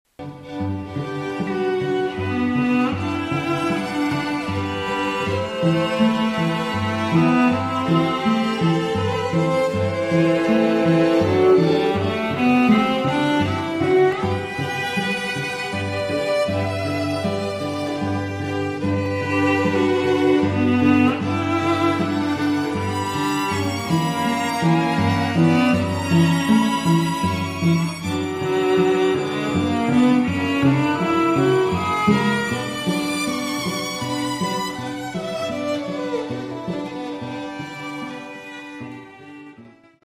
Compare a String Quartet to a String Trio.
trio sample
The addition of a lower strings to a duet adds a rich sound.